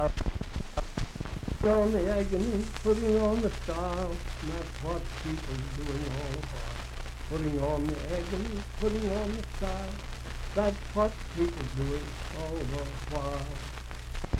Unaccompanied vocal music
in Mount Storm, W.V.
Verse-refrain 1(4).
Voice (sung)